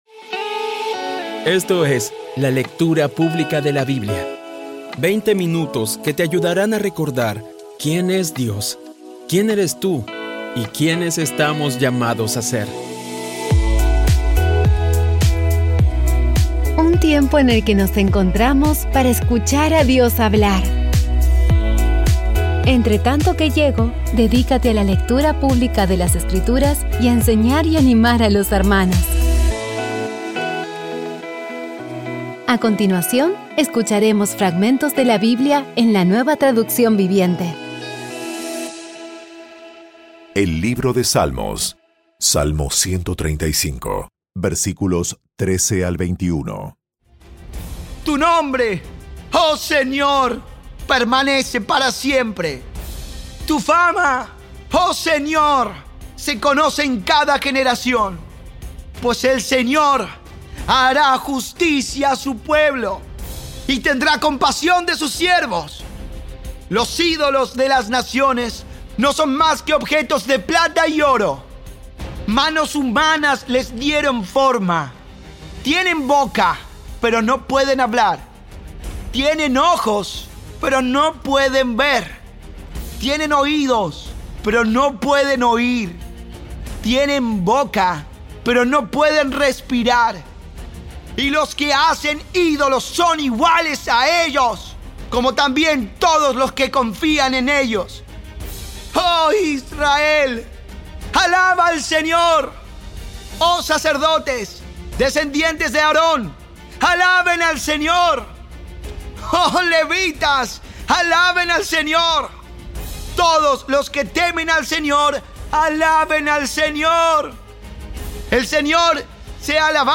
Audio Biblia Dramatizada Episodio 336
Poco a poco y con las maravillosas voces actuadas de los protagonistas vas degustando las palabras de esa guía que Dios nos dio.